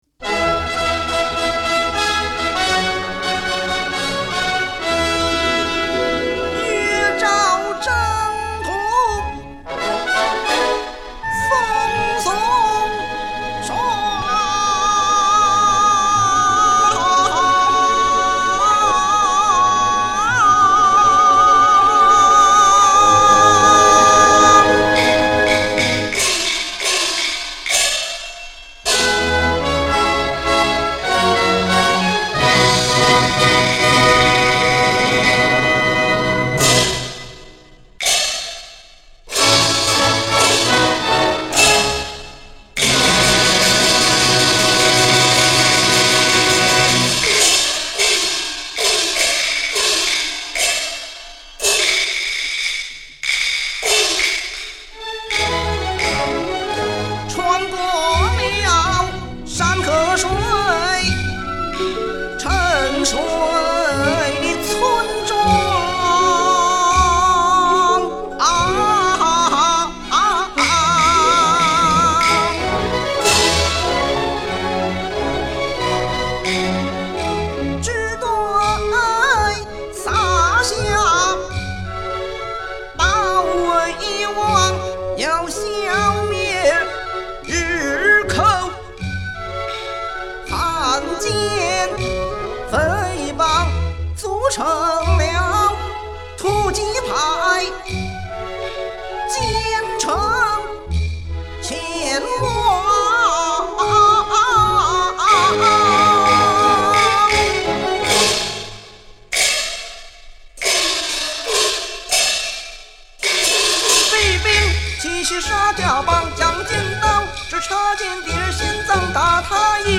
献上样板戏一首，祝各位暑假鱼块！玩好吃好！
这交响乐的伴奏不好唱，唱得太戏味儿不搭配，唱得太歌剧化又没了京味儿。